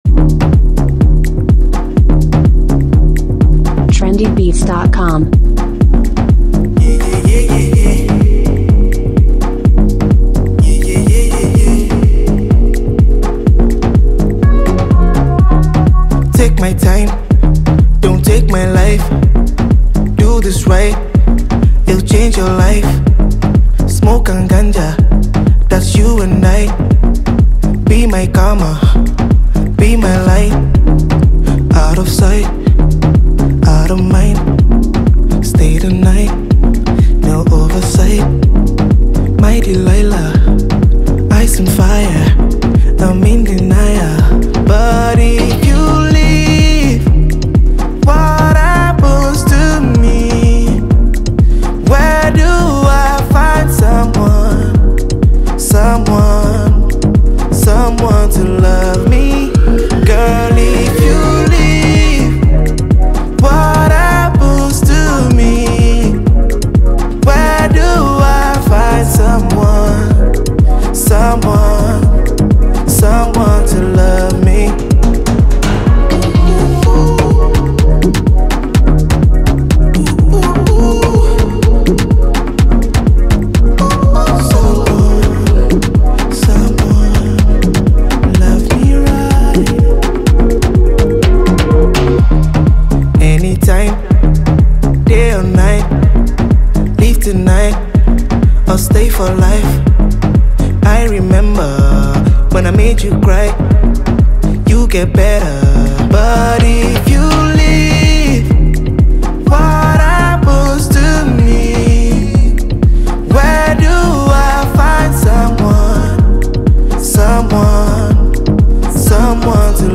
The feel-good tune